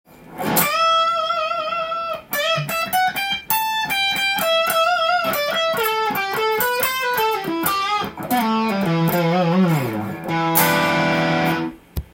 ハムバッカー設定で歪ませて弾いてみました
少し強く弾くことでサスティ―ンが生まれるので
チョーキングした時に気持ちよく弾けますね。